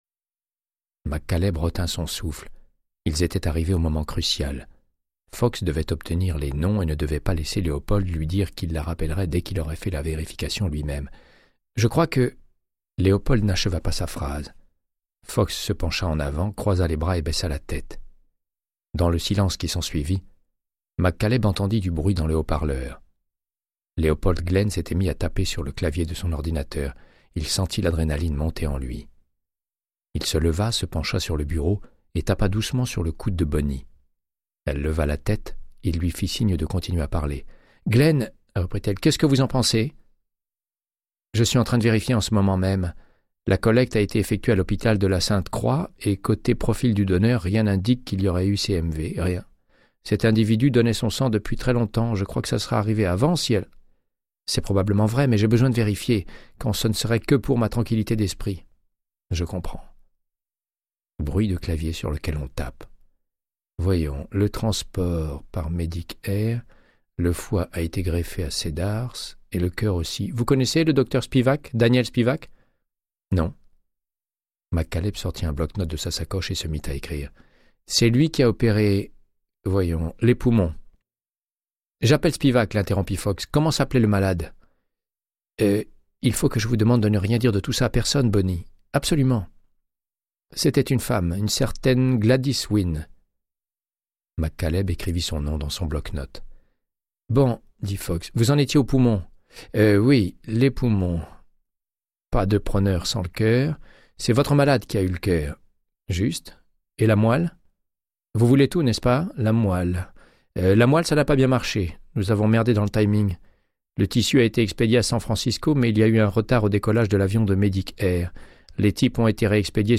Audiobook = Créance de sang, de Michael Connelly - 121